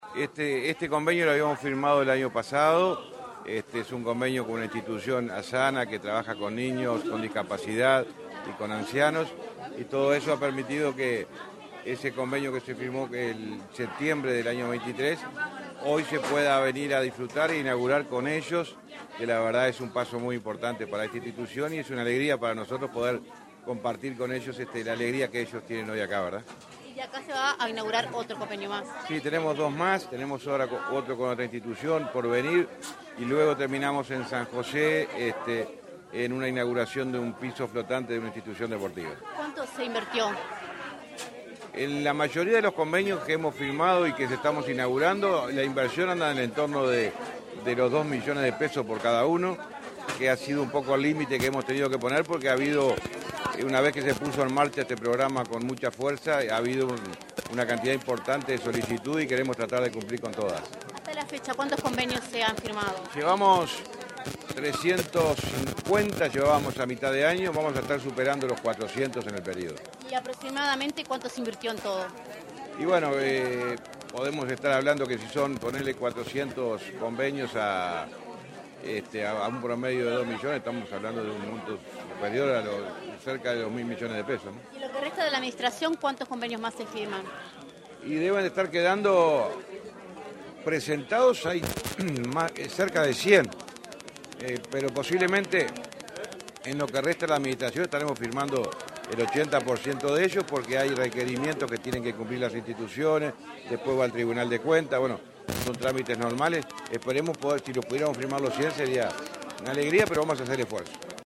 Entrevista al titular del MTOP, José Luis Falero
Entrevista al titular del MTOP, José Luis Falero 11/10/2024 Compartir Facebook X Copiar enlace WhatsApp LinkedIn El Ministerio de Transporte y Obras Públicas (MTOP) inauguró, este 11 de octubre, obras por convenios sociales en San José. Tras el evento, el titular de la cartera, José Luis Falero, efectuó declaraciones a Comunicación Presidencial.